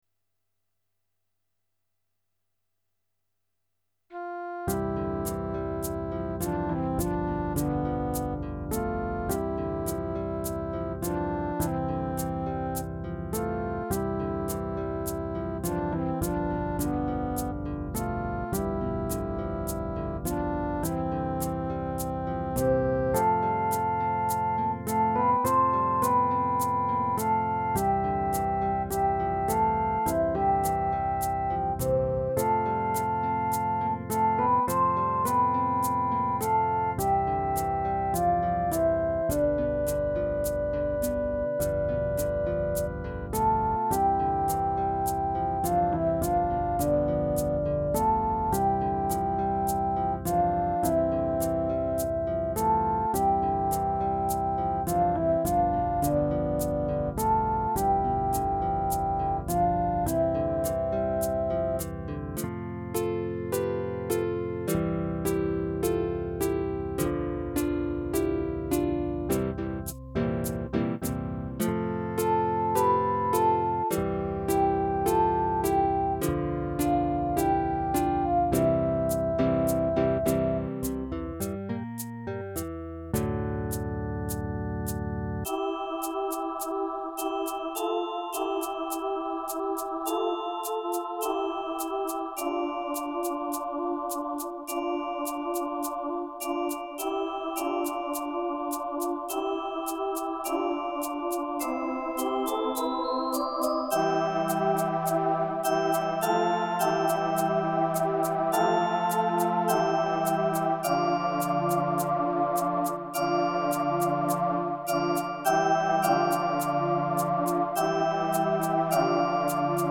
Tuneful and catchy.